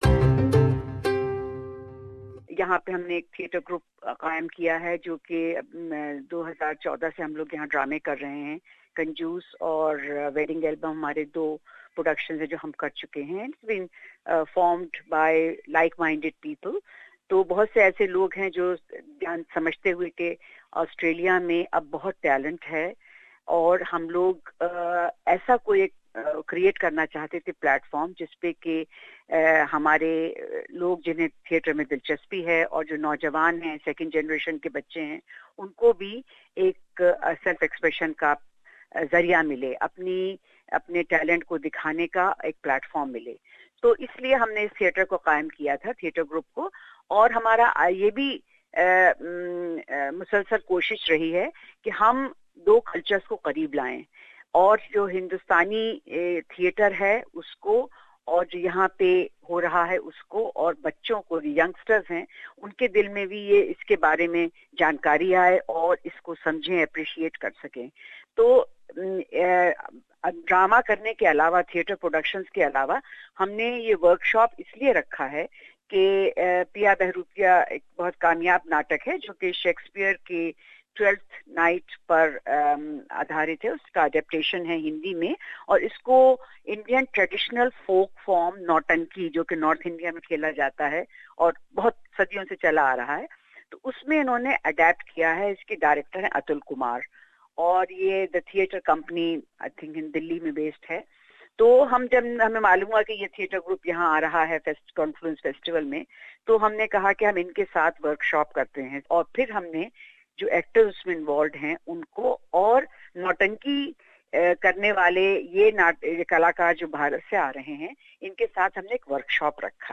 For all those who love theater do tune in for this interview...